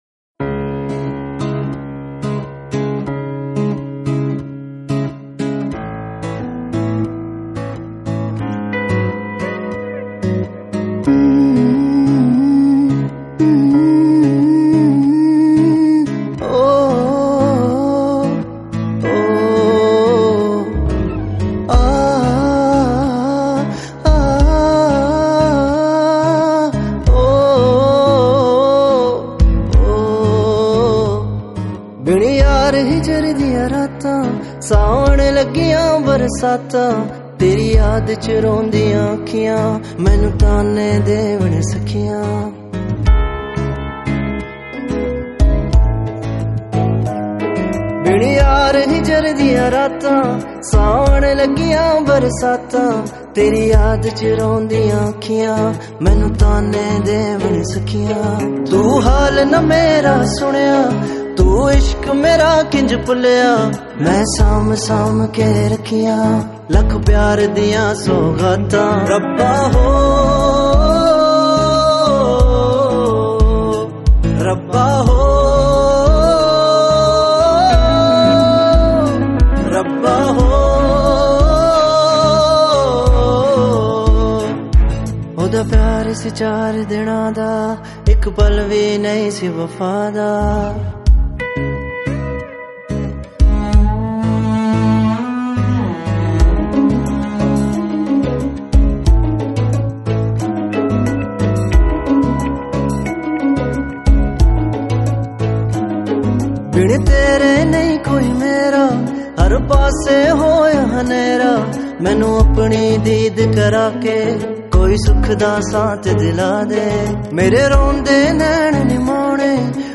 Pop Songs
64 Kbps Low Quality